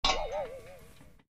Spring muncul dari sofa: